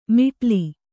♪ miṭli